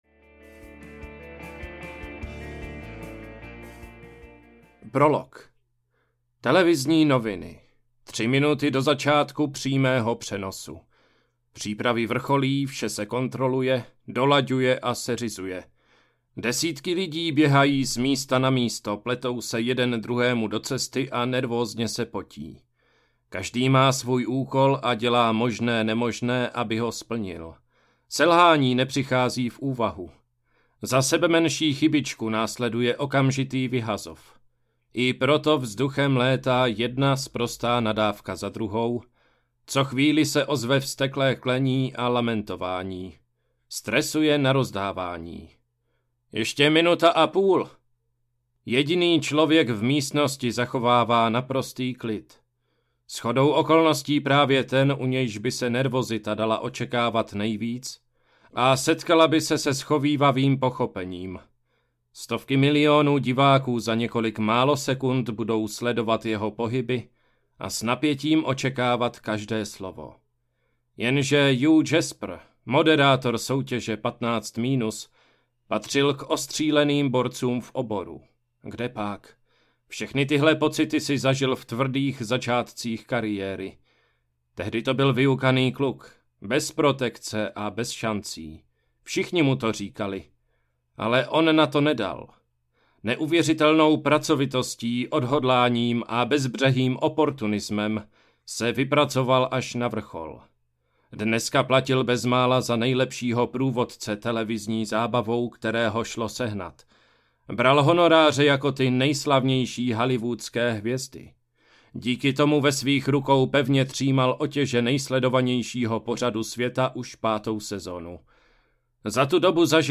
15 minus audiokniha
Ukázka z knihy